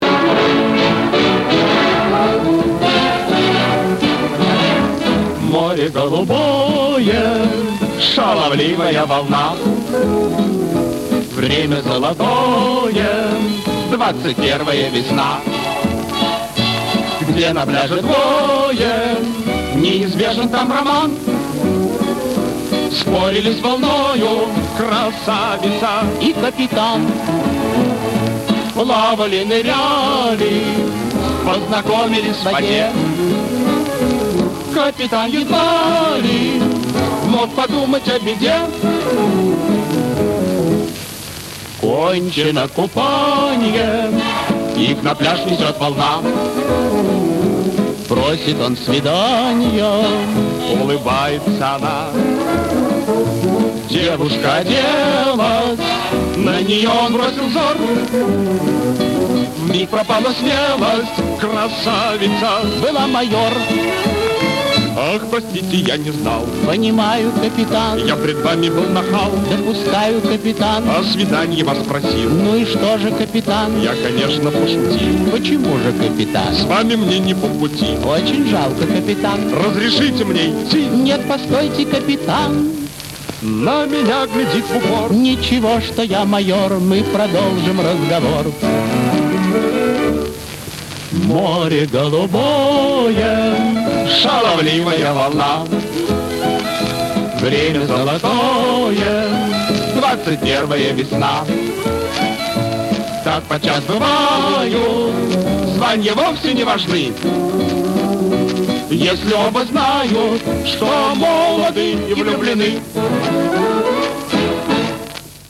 шуточная песенка
Каталожная категория: Дуэт с джаз-оркестром |
Жанр: Песня
Место записи: Ленинград |